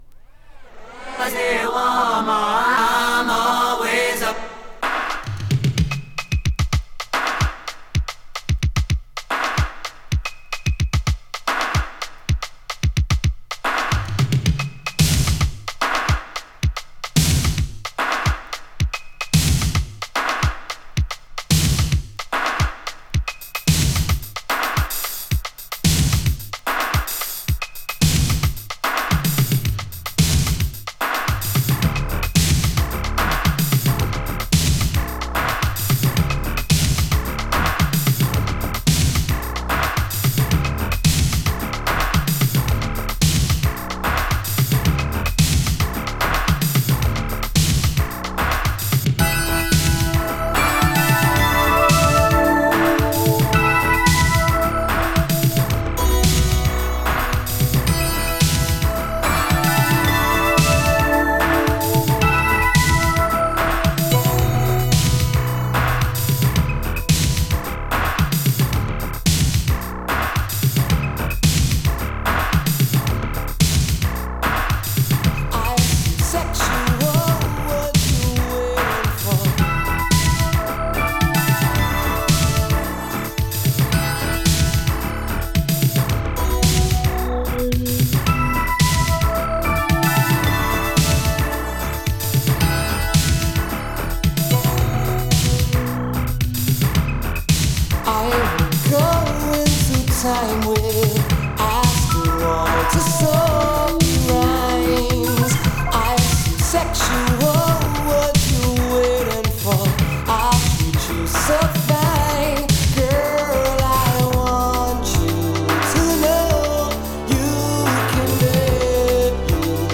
【BOOGIE】 【DISCO】
エレクトロ・ブギー！
ニューウェイヴなテイストも感じられるエレクトリックなブギートラック！